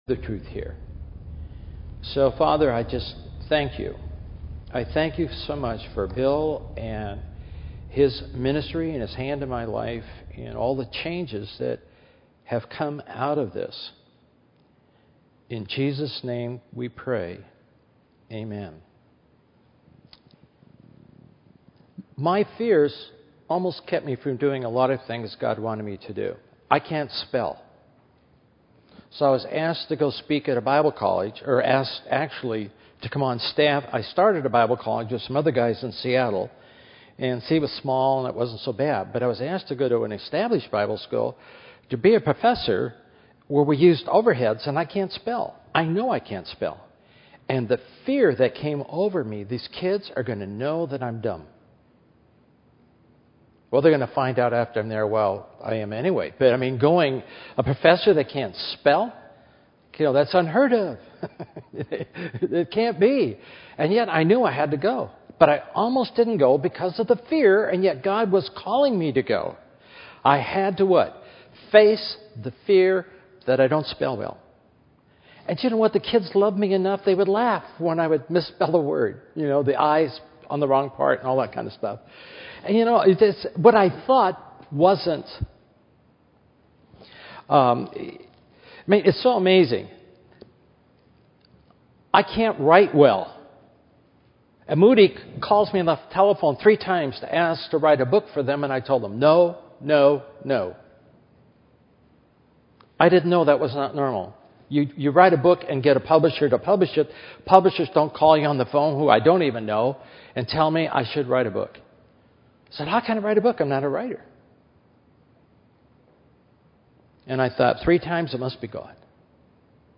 In this sermon, the speaker emphasizes the importance of developing an intimate relationship with God. He shares a story about missionaries who encountered a hostile tribe but were saved when one of them pulled out his false teeth, surprising the attackers.